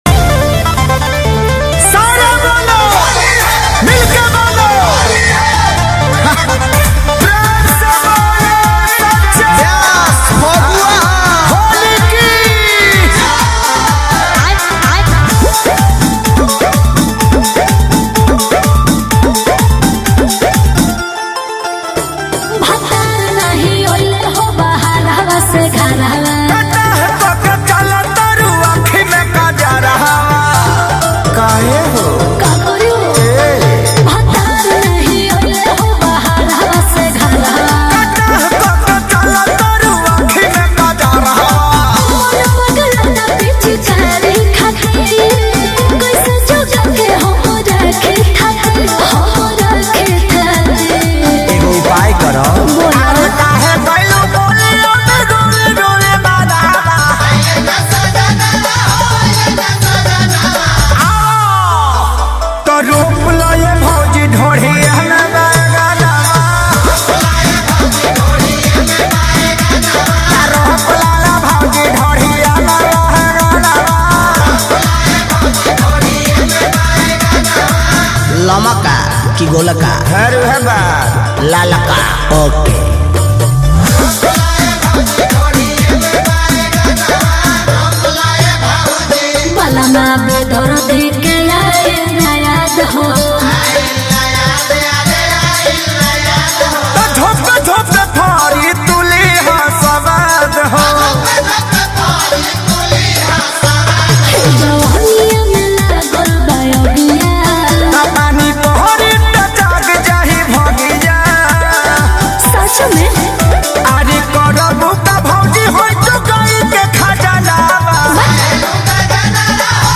Bhojpuri Song